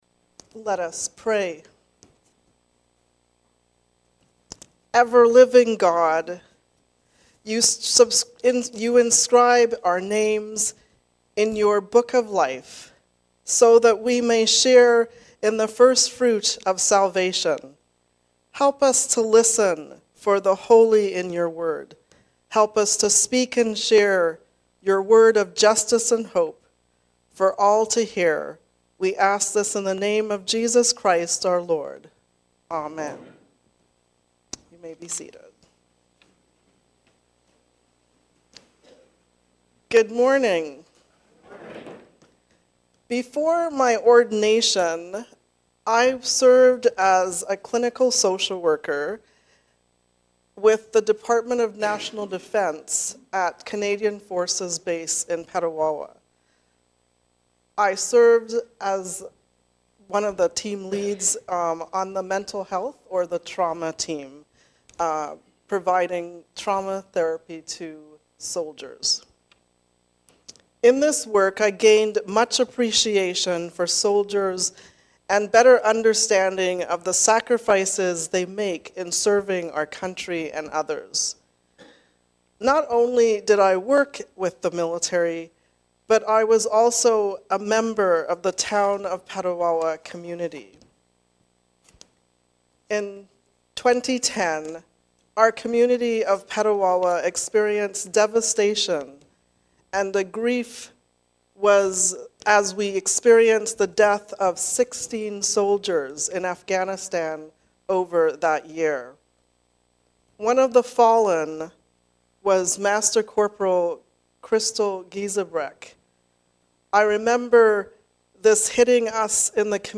Hope for the Ages. A sermon for Remembrance Sunday